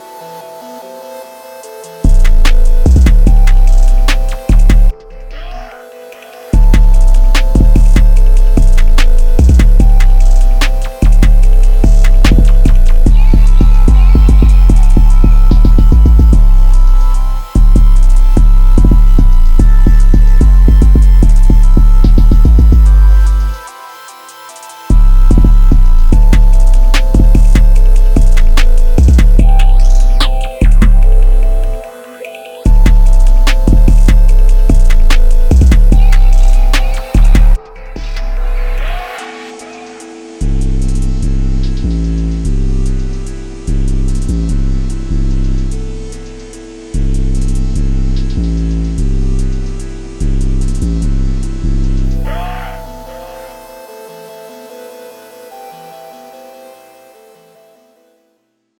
• 50 high-quality, hard-hitting 808 drum samples.
• A variety of tones from clean subs to gritty distortion.
• Perfect for trap, hip-hop, EDM, and more.
Demo Beat: